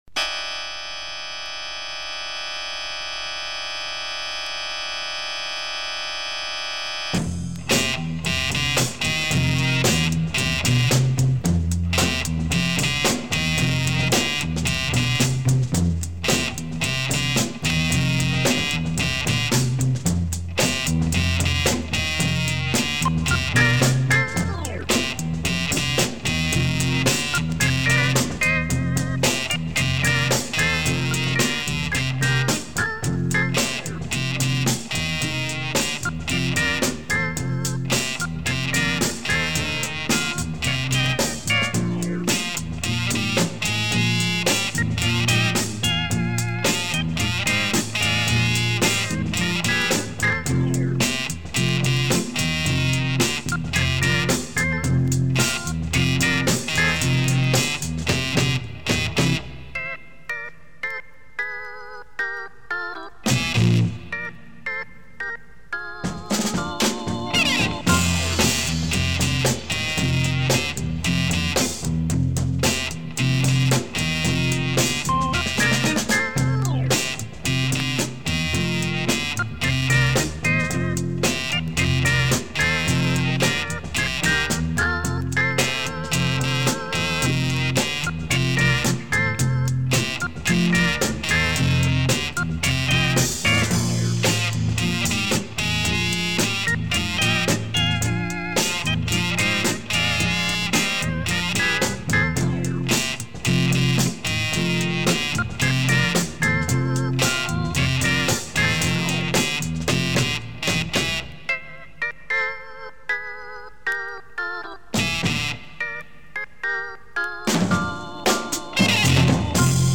Very rare Italian soundtrack
Jazz beat and pop the Italian way...
groovy pop with fuzz and organ.